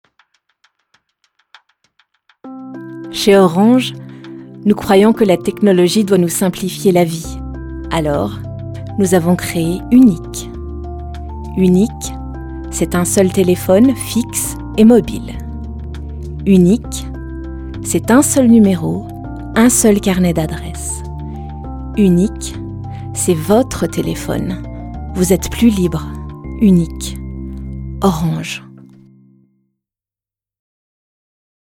a Canadian and French bilingual vo actress with a natural, clear medium voice